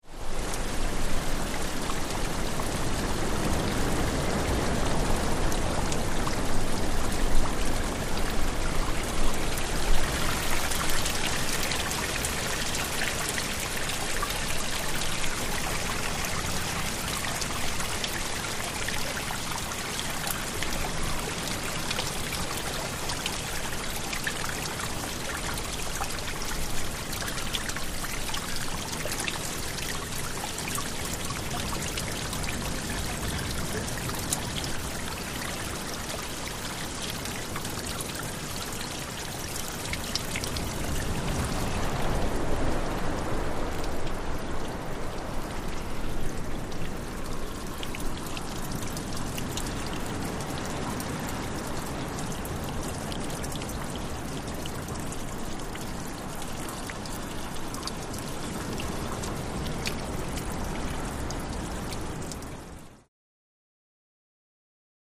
Wave Crashes
Close Up On Mediterranean Sea, Water Draying Through Rocky Cracks.